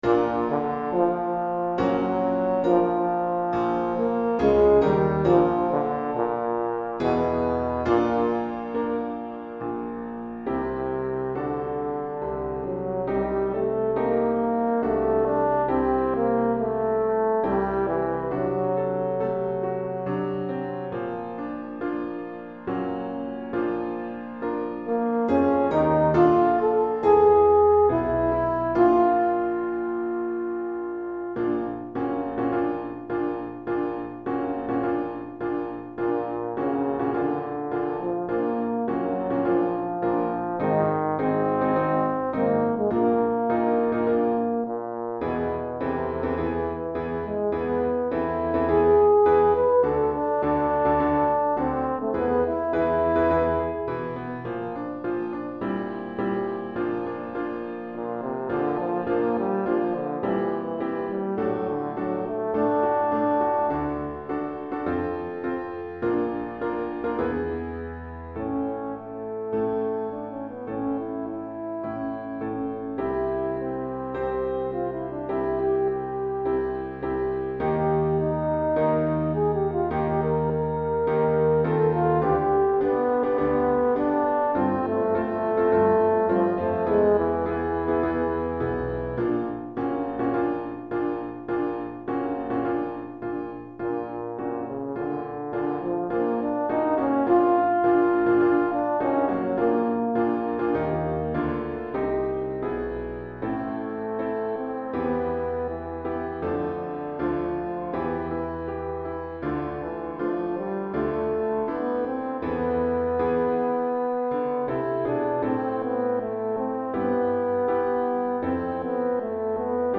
Cor en Fa et Piano